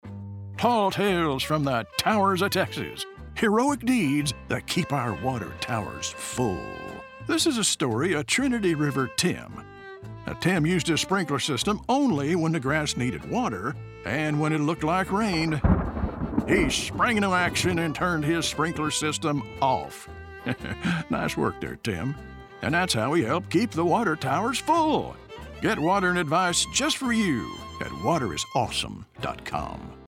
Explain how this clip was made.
Digital Home Studio Shure KSM 32 Large diaphragm microphone